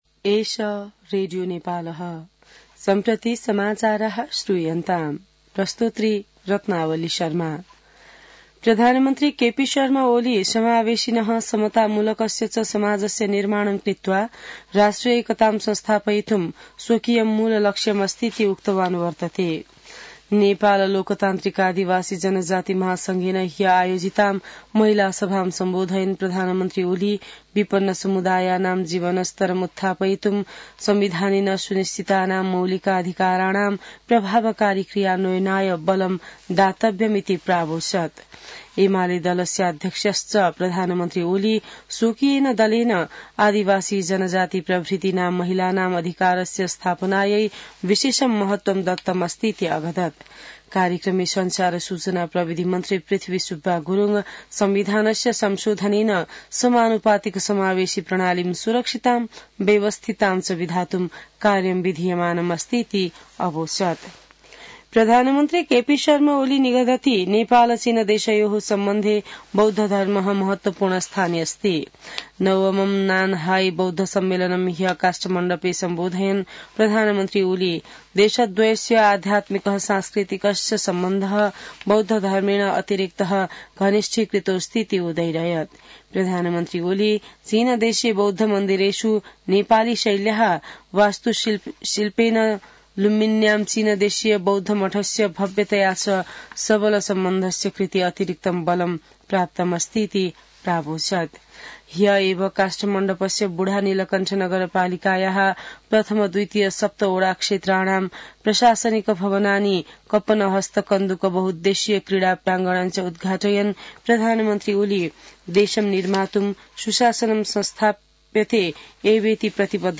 संस्कृत समाचार : ३० मंसिर , २०८१